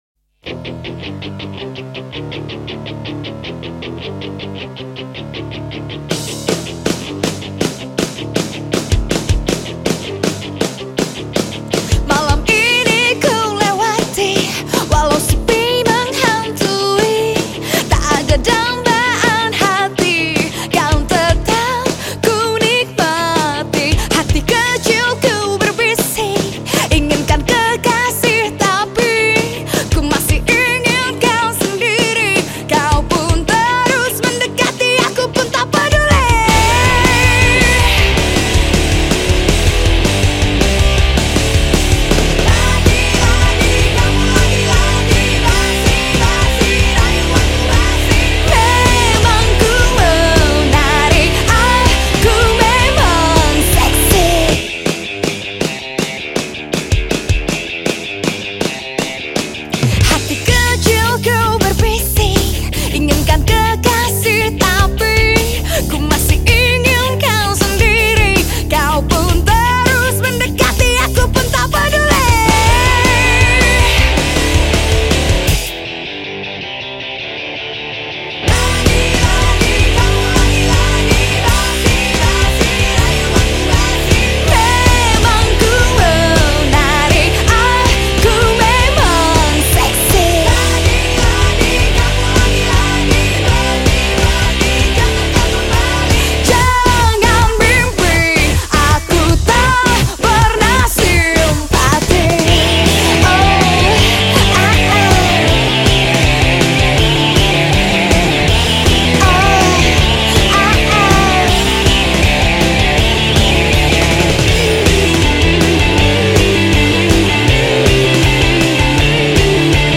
Genre Musik                   : Rock